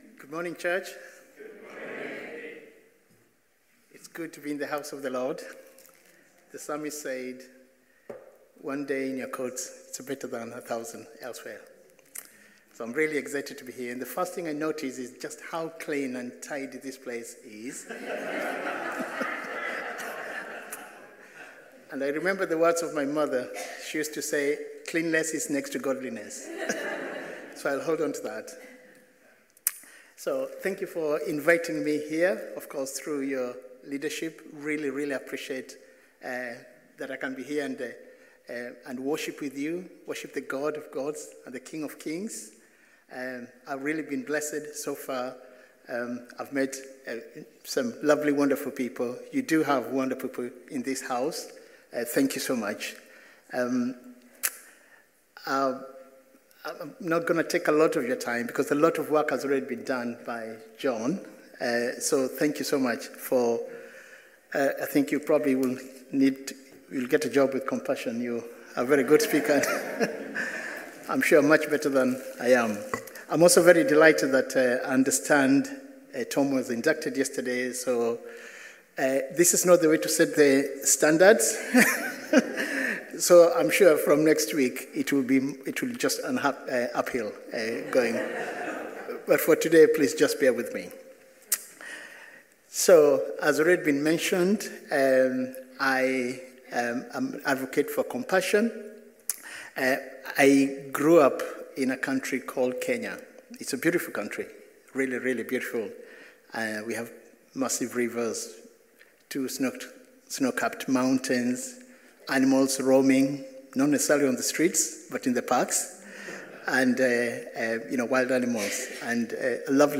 Live stream Passage: Luke 10:25-37 Service Type: Sunday Morning